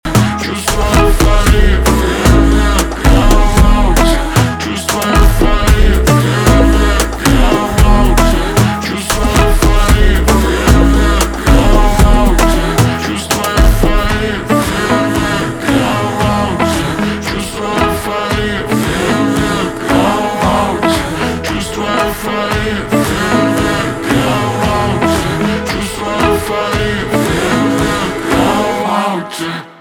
поп
гитара , хлопки , барабаны